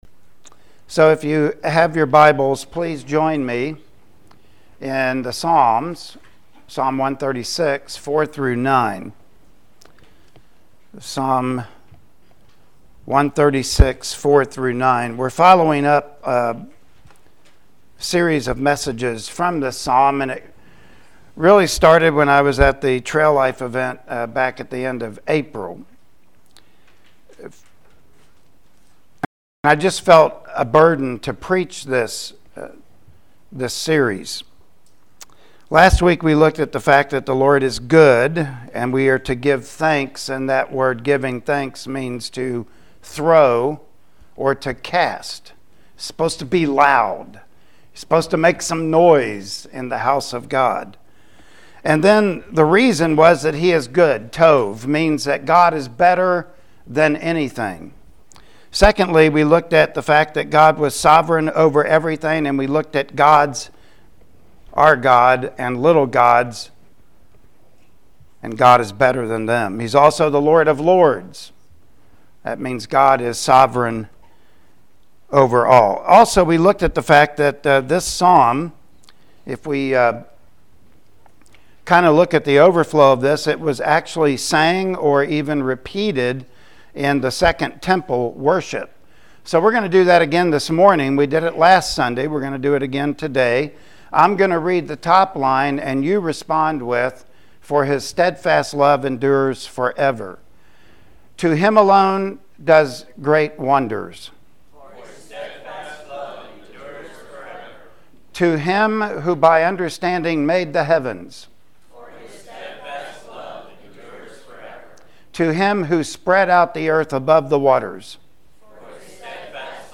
Psalm 136 Passage: Psalm 136:4-9 Service Type: Sunday Morning Worship Service Topics